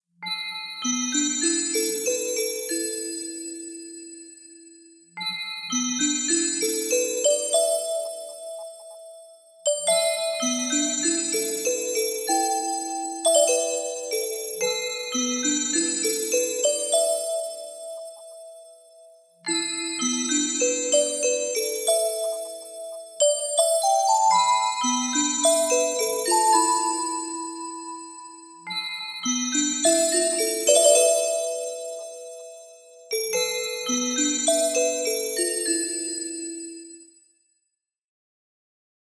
クリスタルバージョンでは、音の透明感や清らかさをさらに引き立てたアレンジが施されています。